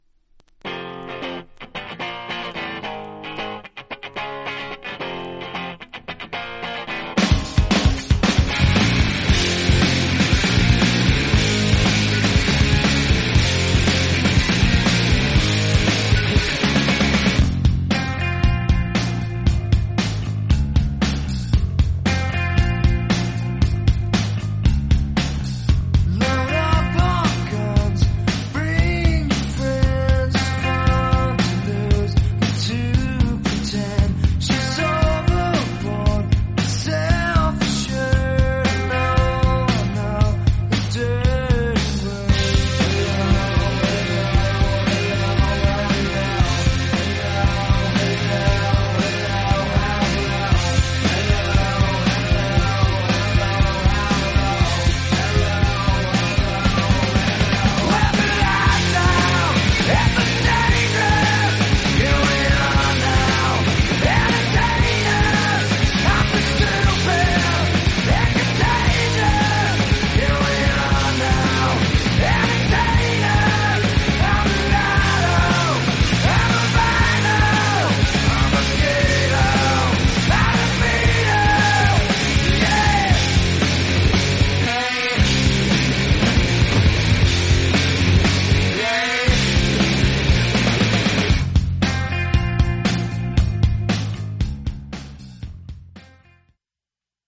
実際のレコードからのサンプル↓